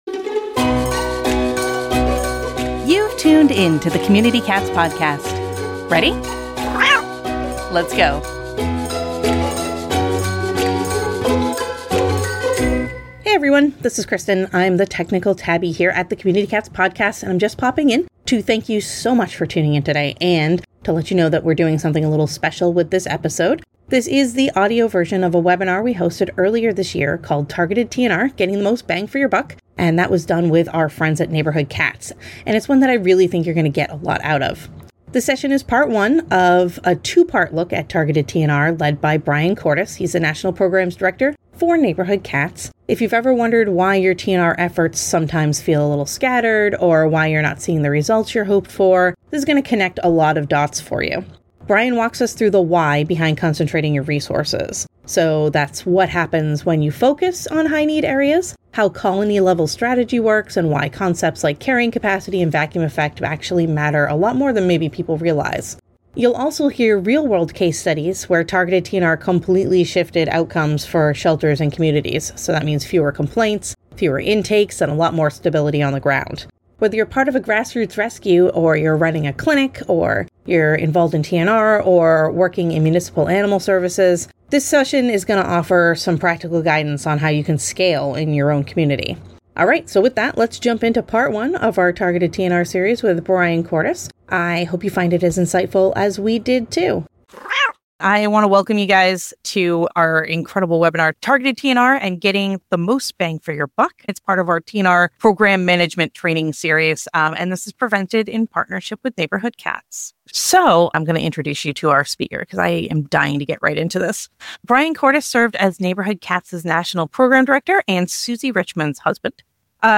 In Part 1 of this two-part webinar